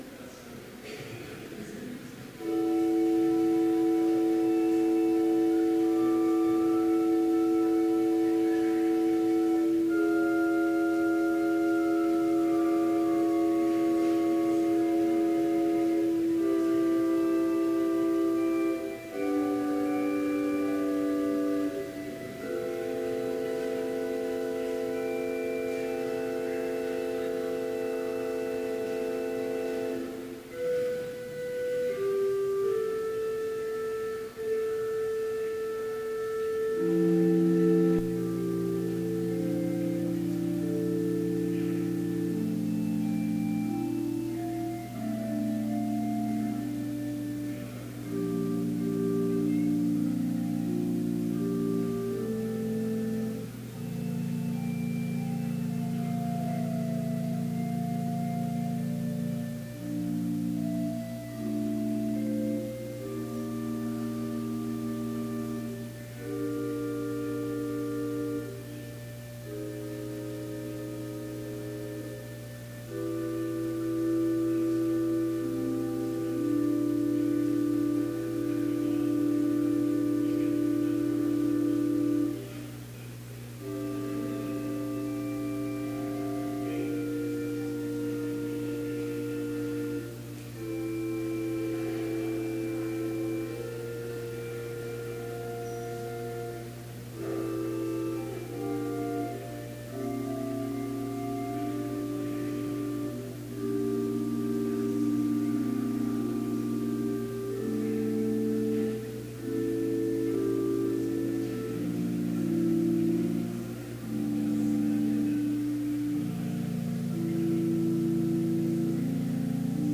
Chapel service on April 24, 2017, at Bethany Chapel in Mankato, MN, (video and audio available) with None Specified preaching.
Complete service audio for Chapel - April 24, 2017
Easter Hymn Sing
Organ introduction